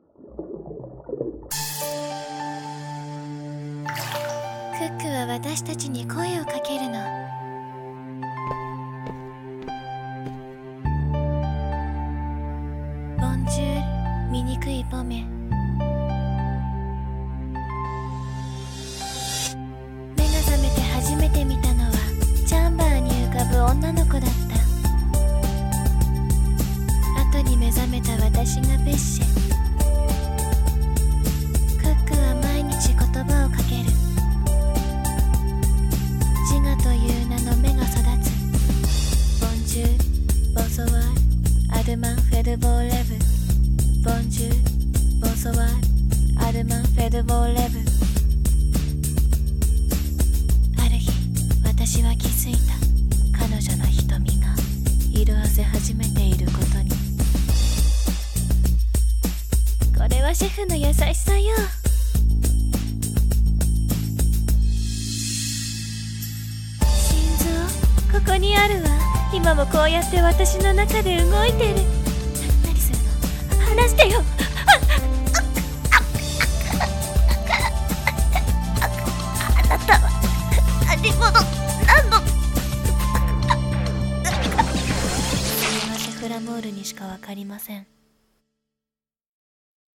CM風声劇「ポミェとペッシェ」